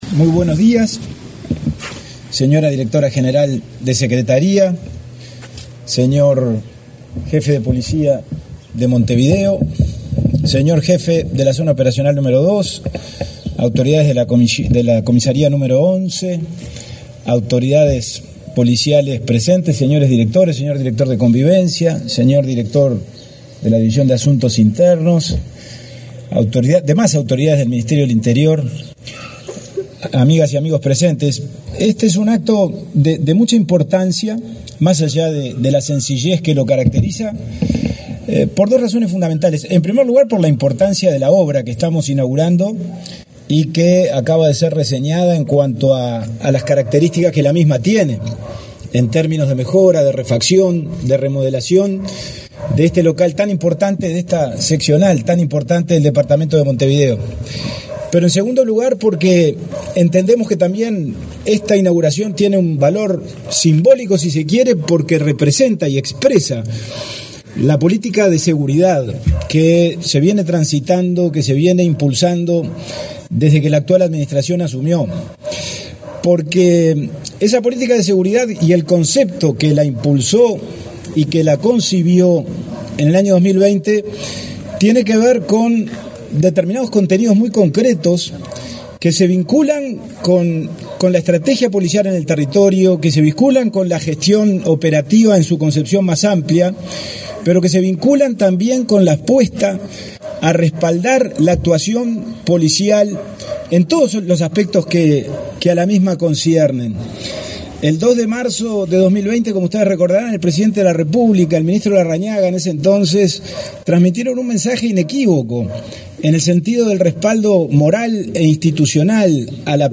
Palabras del subsecretario del Ministerio del Interior, Pablo Abdala
Palabras del subsecretario del Ministerio del Interior, Pablo Abdala 25/06/2024 Compartir Facebook X Copiar enlace WhatsApp LinkedIn El Ministerio del Interior inauguró, este 25 de junio, las obras de remodelación y acondicionamiento de la seccional 11.ª de Montevideo. En el evento disertó el subsecretario Pablo Abdala.